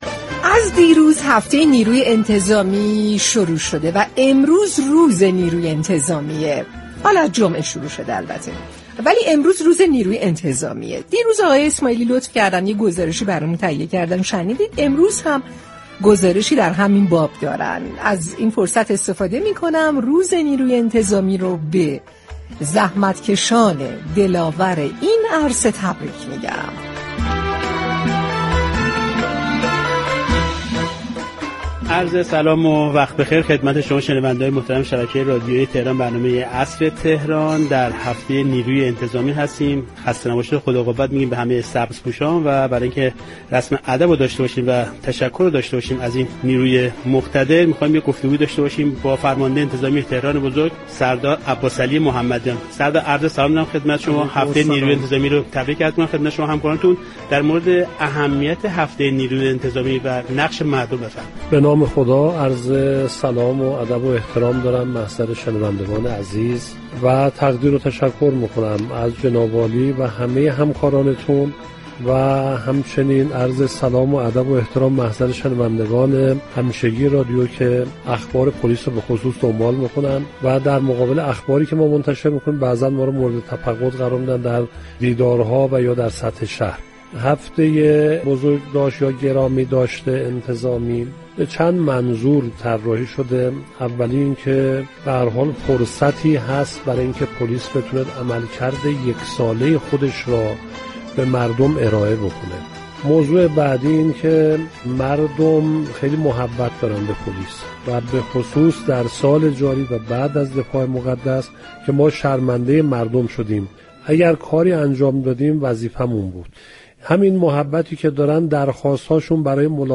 پلیس در خدمت آرامش مردم؛ گفت‌وگوی رادیو تهران با سردار محمدیان در هفته نیروی انتظامی+فایل صوتی